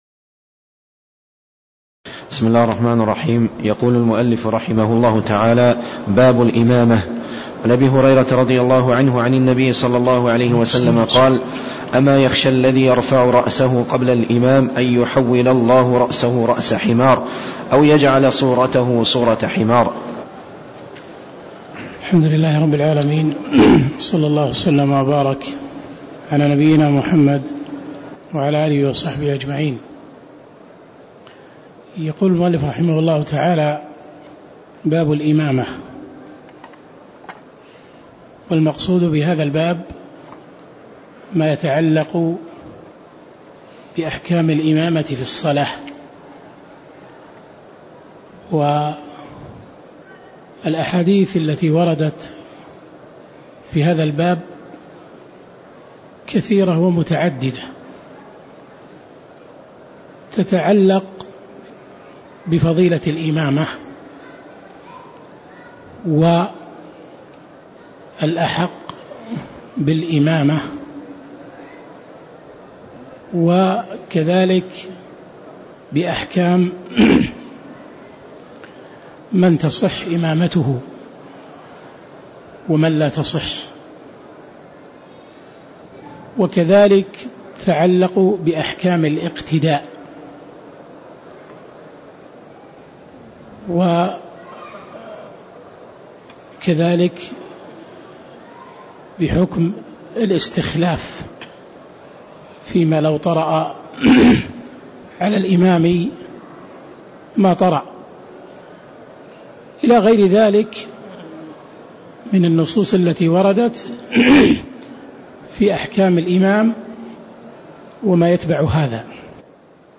تاريخ النشر ٢١ رجب ١٤٣٦ هـ المكان: المسجد النبوي الشيخ